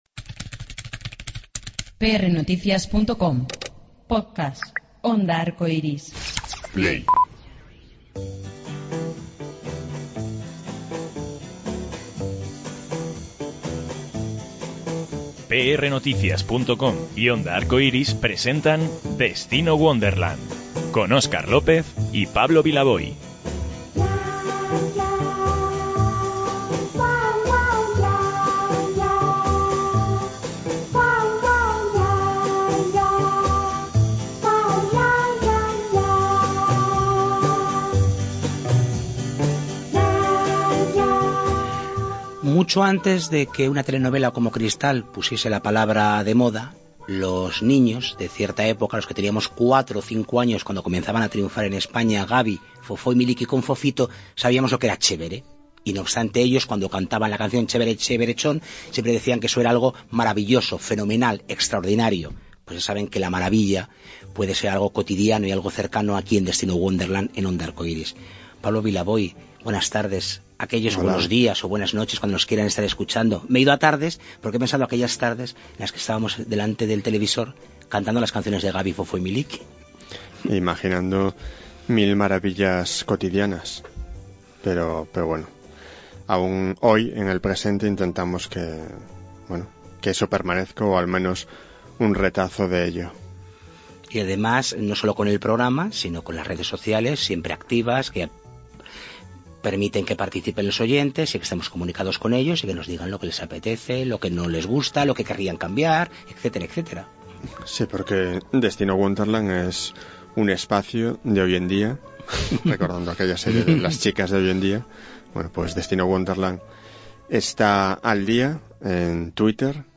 Miguel Rellán cita a ‘Destino Wonderland’ en una librería del centro de Madrid para tomar un café y charlar distendidamente sobre el arte de la interpretación en el que tanto tiempo lleva demostrando su maestría.